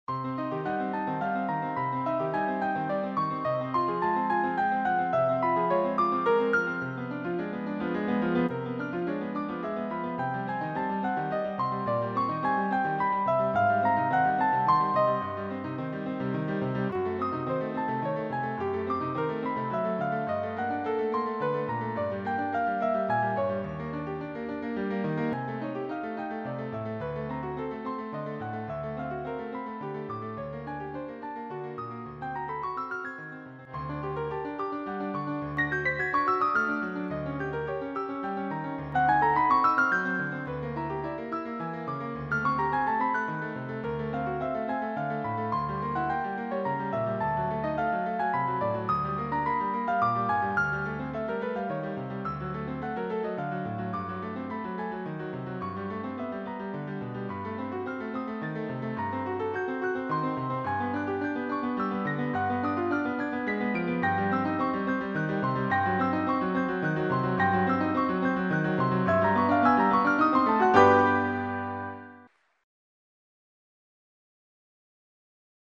Ceci est une tentative instrumentale de traiter la grisaille / camaieu. La couleur de fond est le timbre propre au piano (ici 2), la pièce de Bach est ici le dessin principal dont la densité est passé au crible de la résultante dodécaphonique de chaque mesure, modifiant ainsi les nuances d’une même couleur qu’est l’architecture de la pièce originale.
Ein kleiner graue Bach stereo.mp3